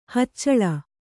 ♪ haccaḷe